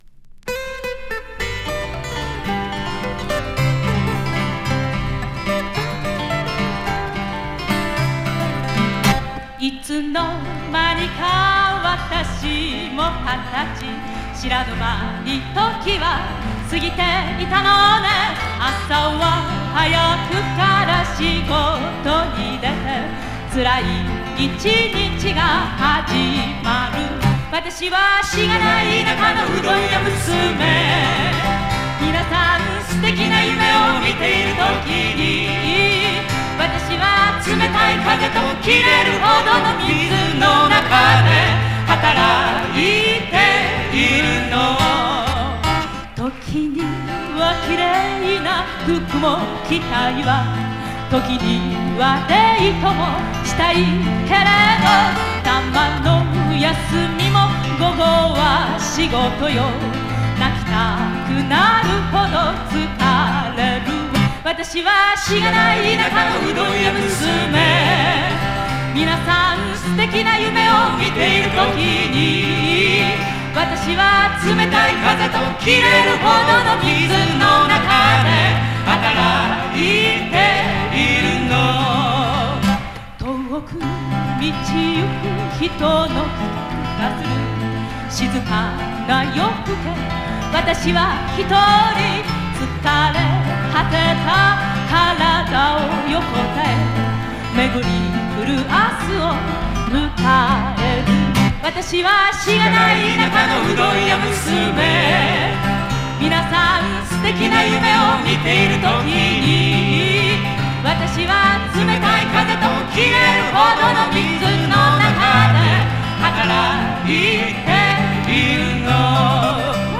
女性SSW